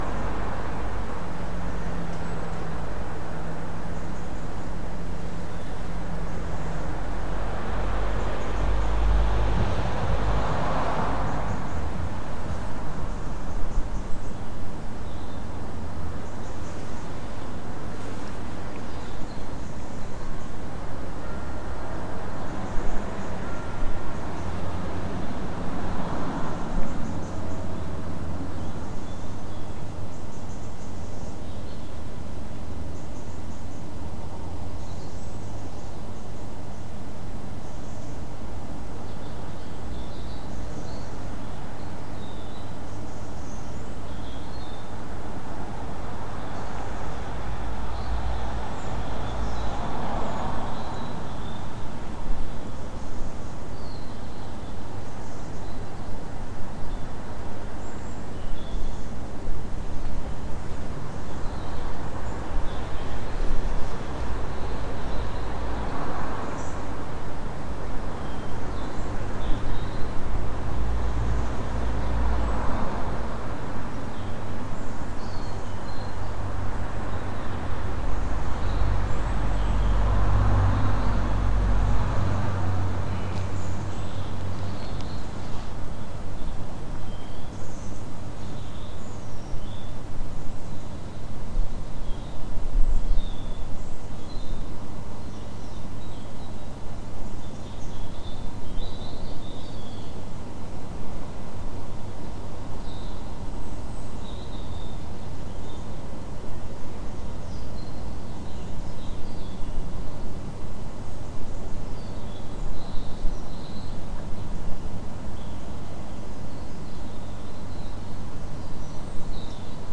Quarter to seven Birds. Gets more interesting partway through